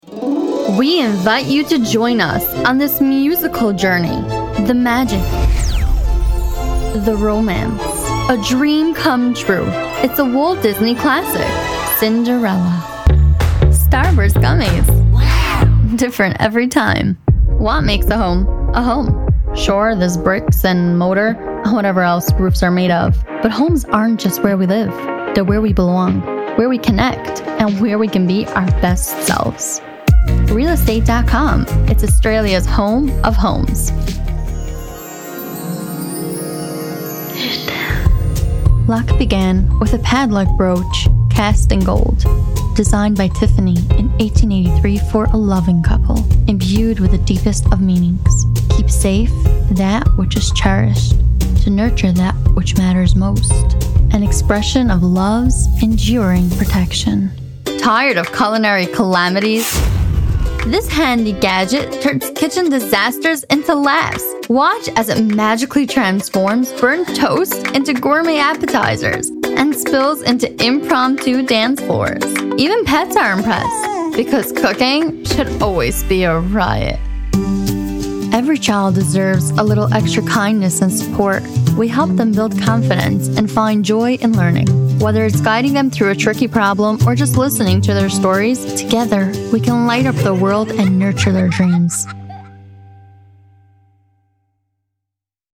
• English (American)
• youthful
• adult
• mature